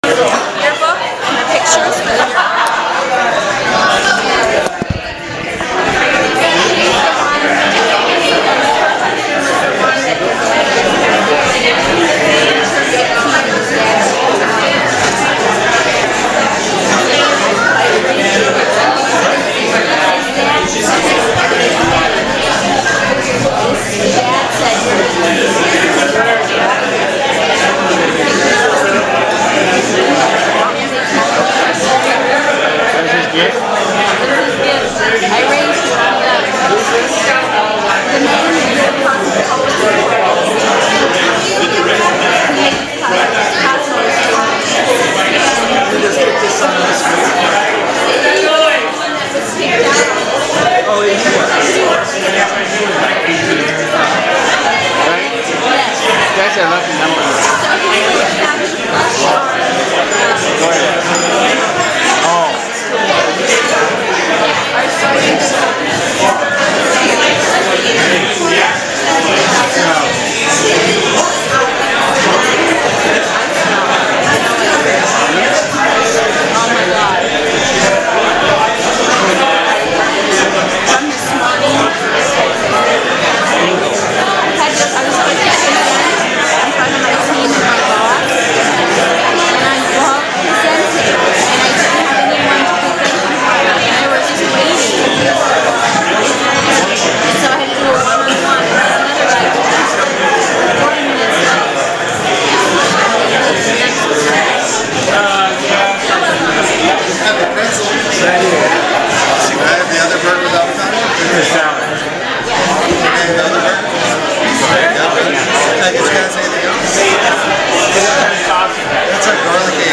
Busy_Restaurant.mp3